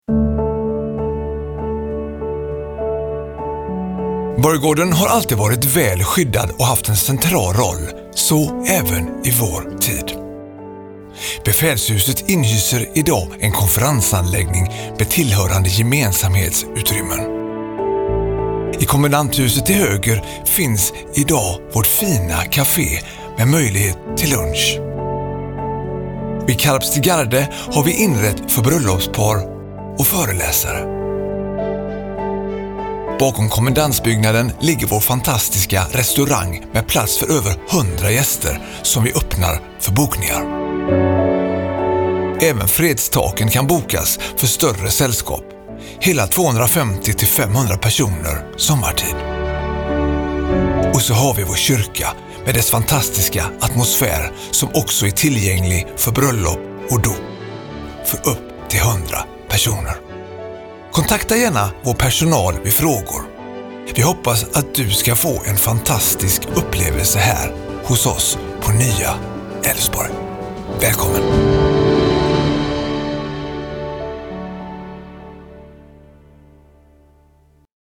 Berättarröst:
Denna guidespot: 14. Borggården